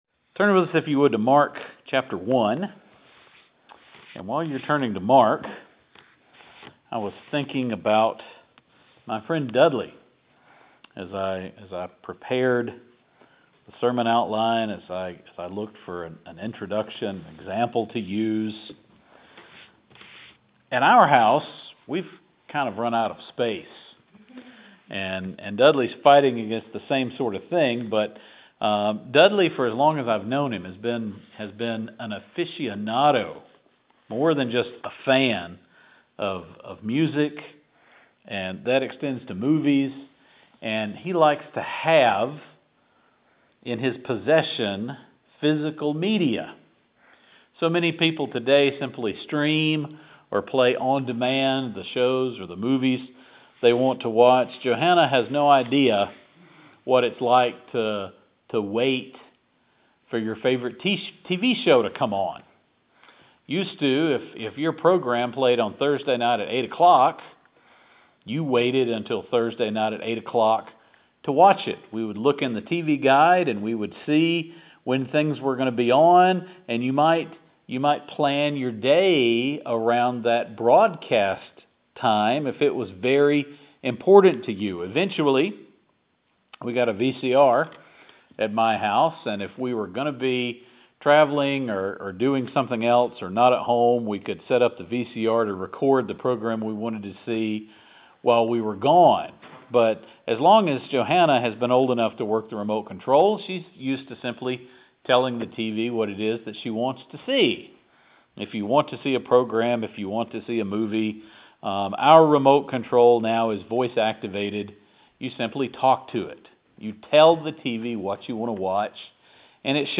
After an awkward introduction – the illustration was great I just couldn’t remember for a moment how it related to the sermon – this really turned around. The lost need to hear the Gospel, Christians need to be reminded of it, and ultimately it is why Jesus came to this world.